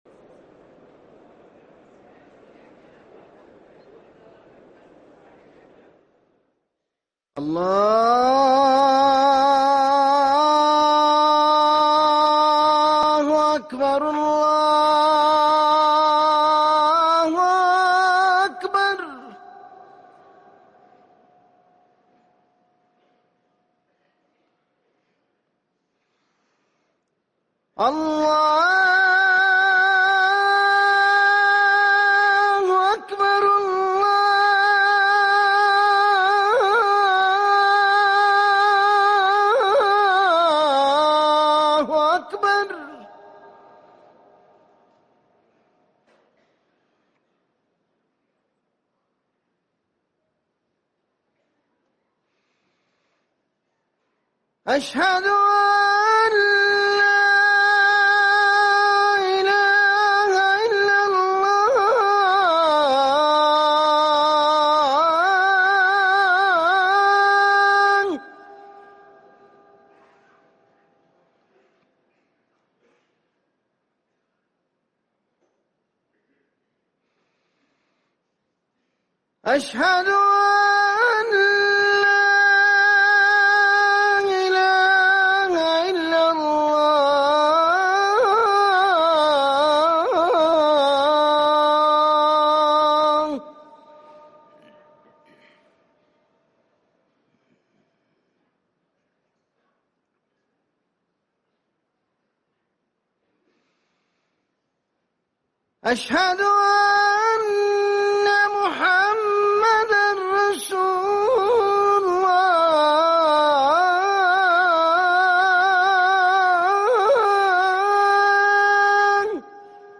اذان العصر